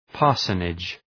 Προφορά
{‘pɑ:rsənıdʒ}